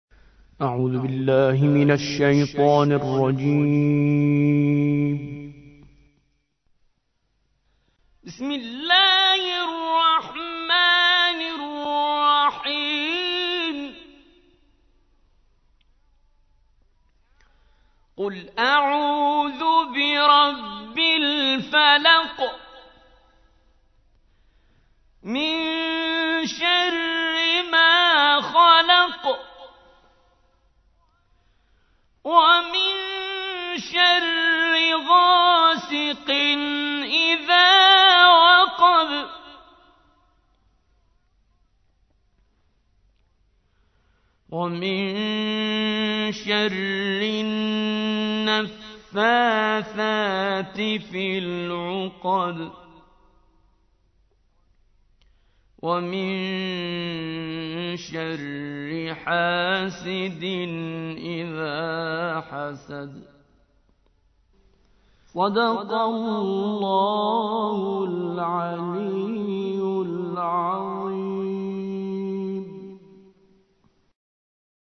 113. سورة الفلق / القارئ